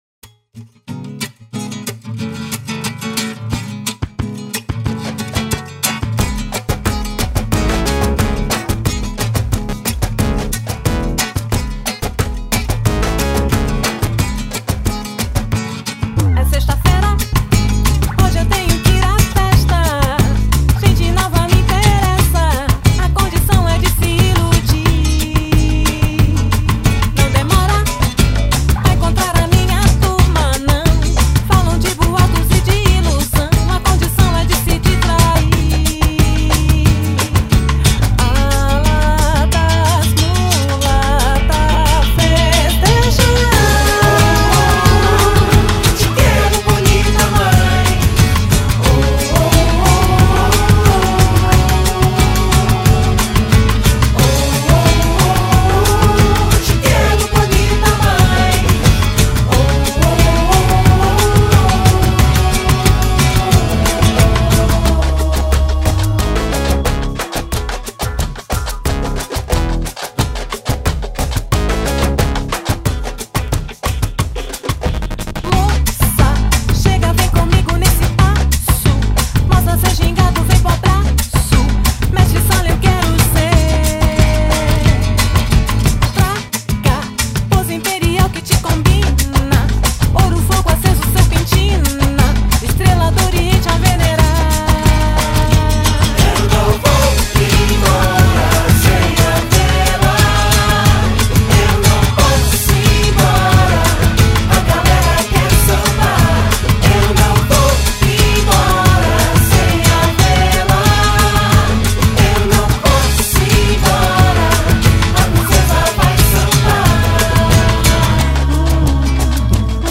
Brazil / Electro.
Trashy dancefloor beats and amazonian hymns dominate